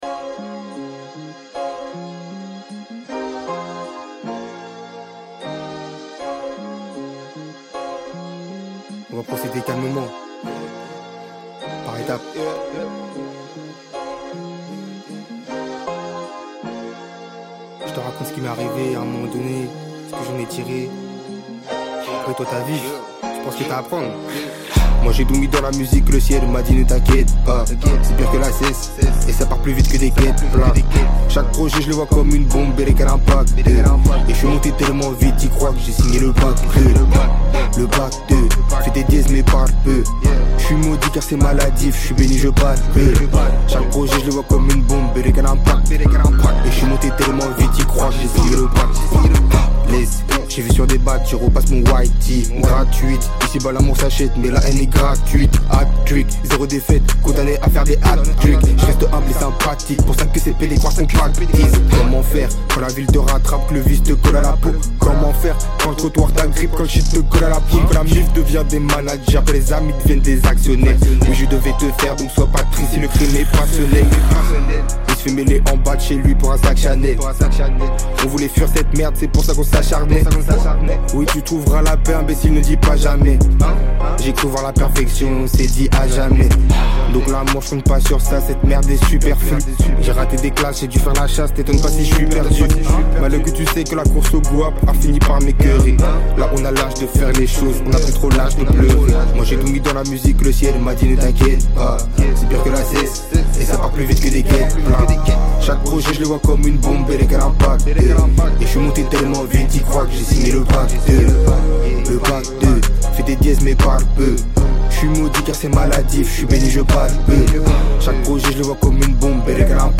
Genres : french rap